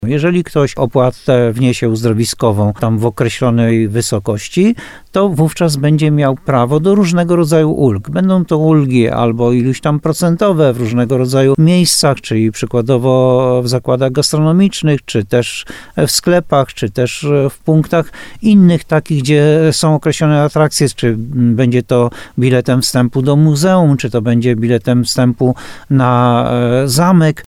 – mówi Jan Golba burmistrz Muszyny.